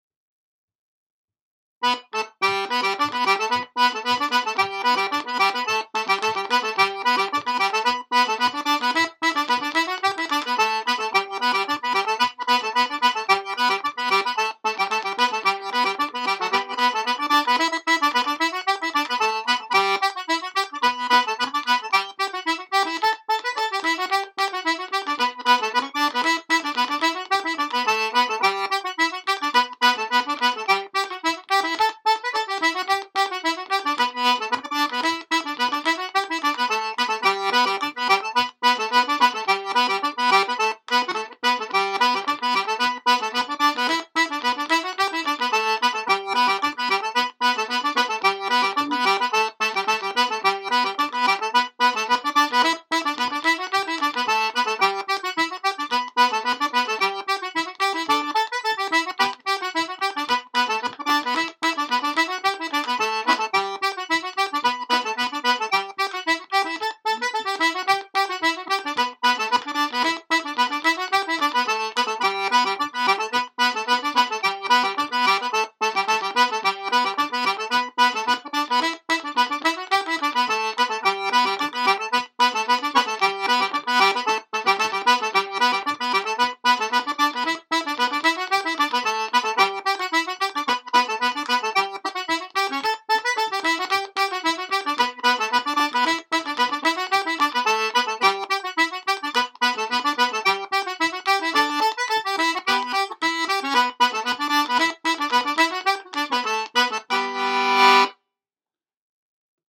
Miss McCleod_s (110 bpm) – Sean-nós & Set Dance